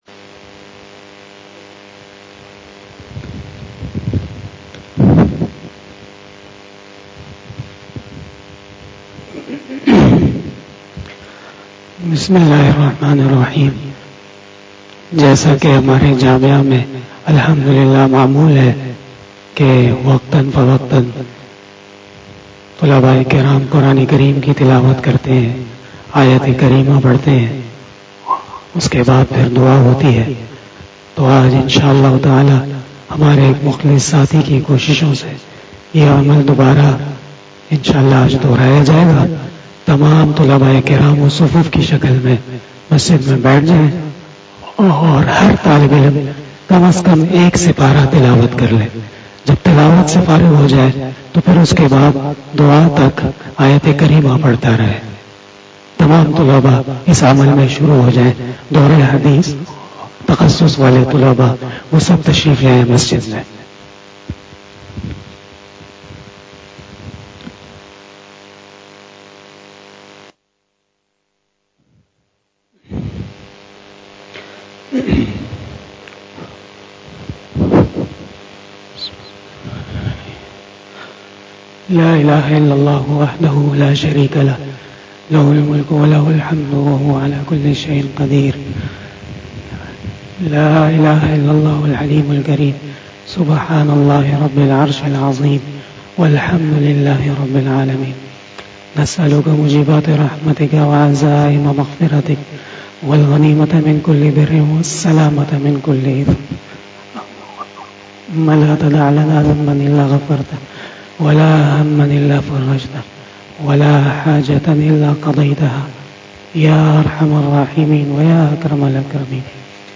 Dua 03 feb 2021
Dua After Isha Namaz Bayan